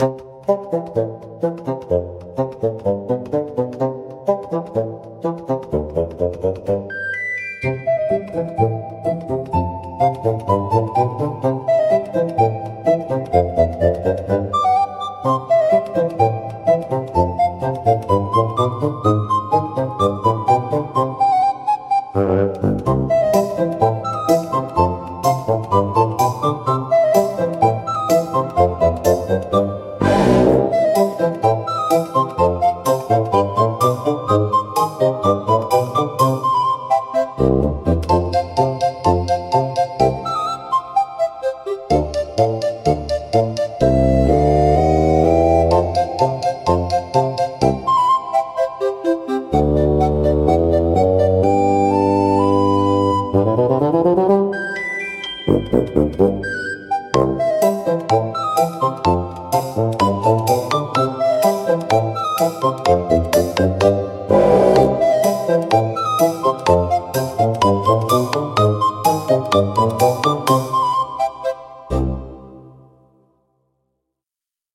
おとぼけは、バスーンとシロフォンを主体としたコミカルでドタバタした音楽ジャンルです。
視聴者の笑いを誘い、軽快で親しみやすいムードを演出しながら、退屈を吹き飛ばします。